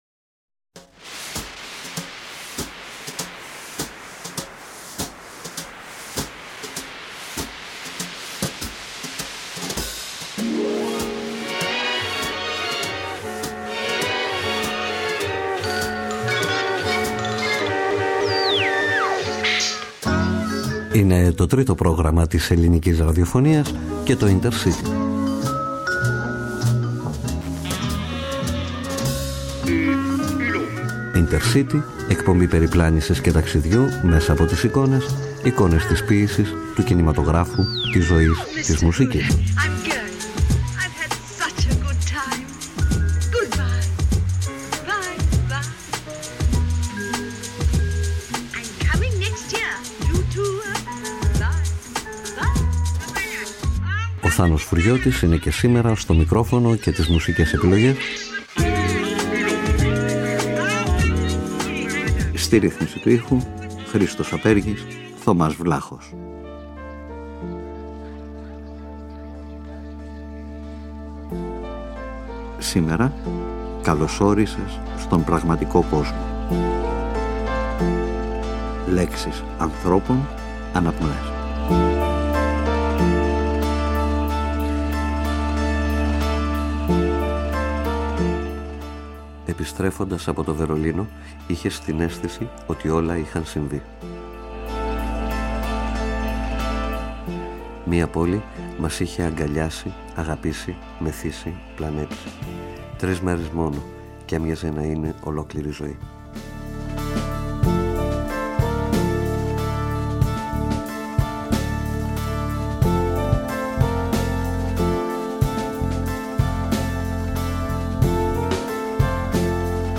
(Λέξεις ανθρώπων – αναπνοές – μουσικές)